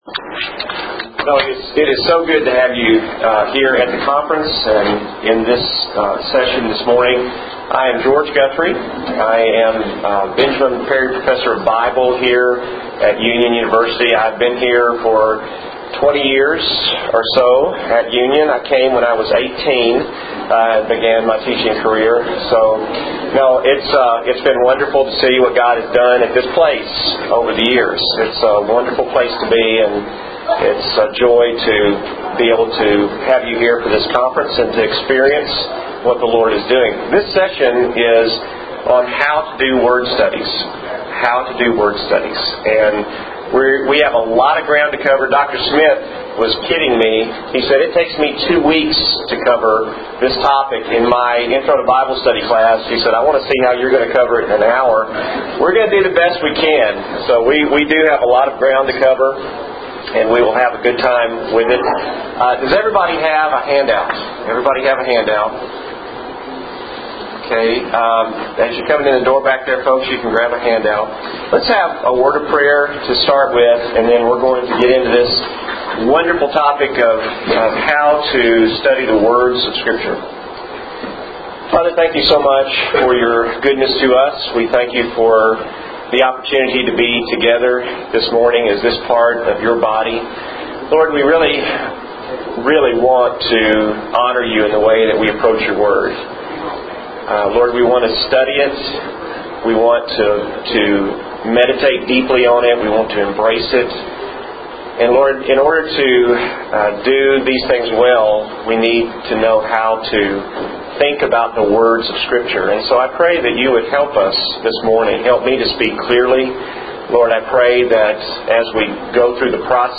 Breakout Session